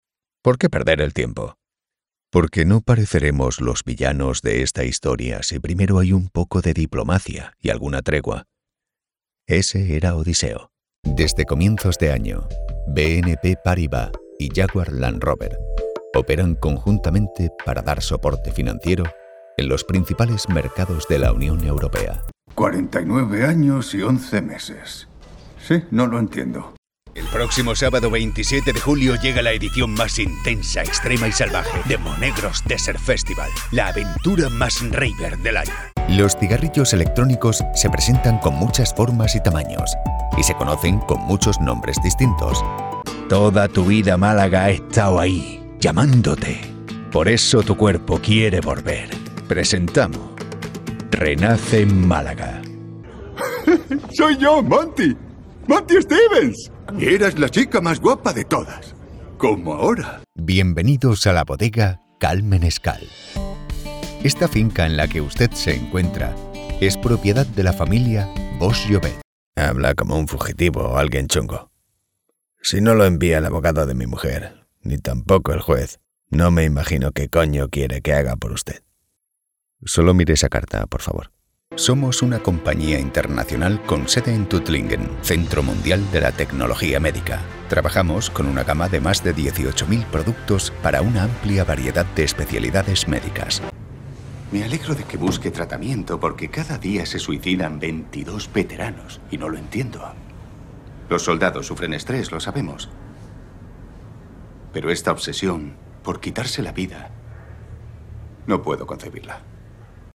Adult (30-50) | Older Sound (50+)